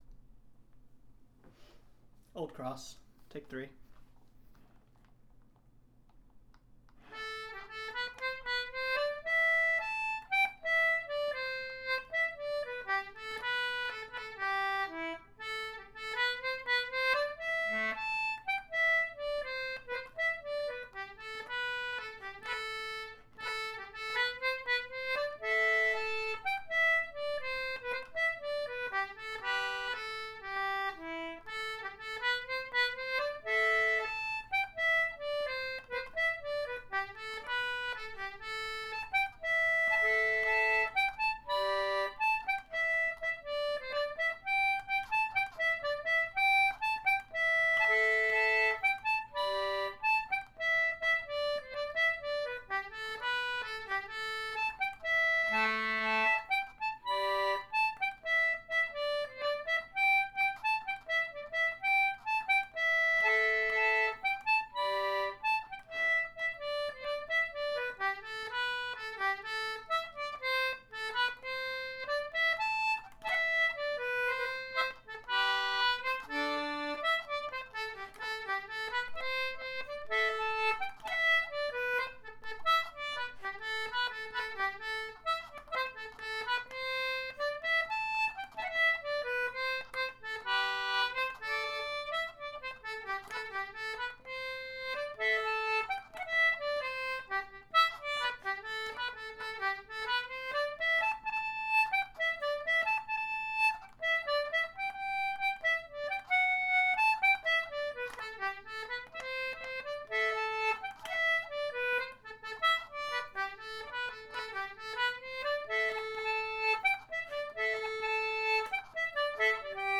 March and hornpipe on concertina.